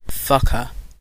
Ääntäminen
UK : IPA : [ˈfʌk.ə]